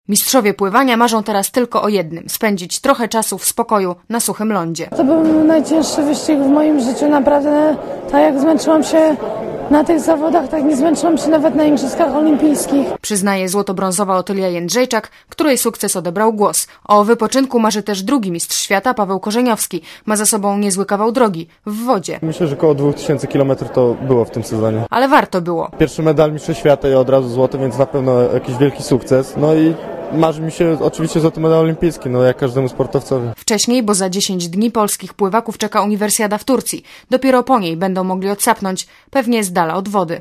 Źródło zdjęć: © PAP 02.08.2005 19:48 ZAPISZ UDOSTĘPNIJ SKOMENTUJ Relacja reportera Radia ZET